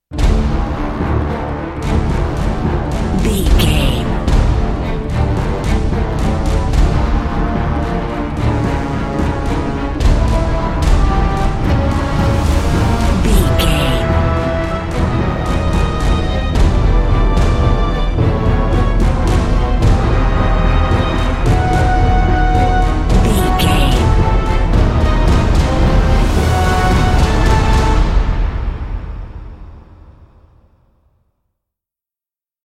Epic / Action
Fast paced
Aeolian/Minor
D
strings
drums
horns
orchestral
orchestral hybrid
dubstep
aggressive
energetic
intense
powerful
bass
synth effects
wobbles
heroic
driving drum beat
epic